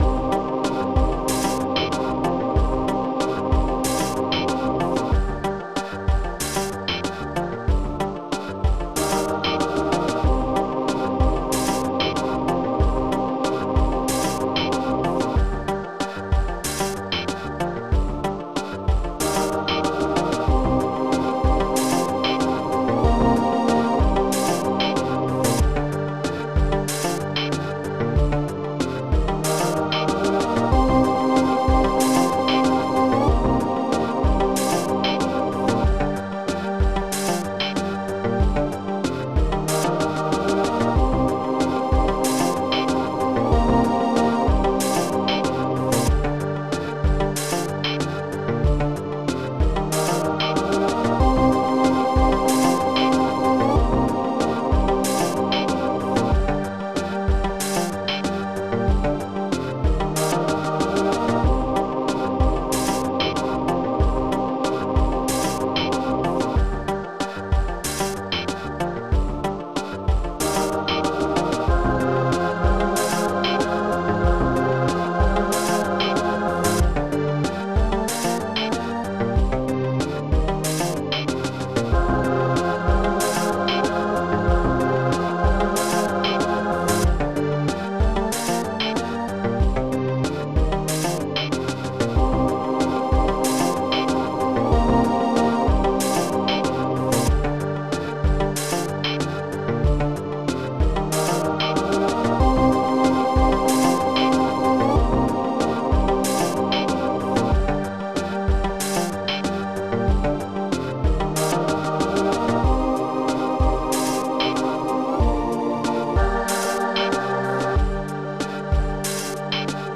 Laidback3.mod Type Amos Music Bank Tracker
Instruments ST-12:bassdrum.d ST-12:snare34 ST-11:hihatop.u1 ST-11:metal.u110 ST-11:majchoir.u ST-11:minchoir.u ST-09:ac1 ST-12:elorg-stri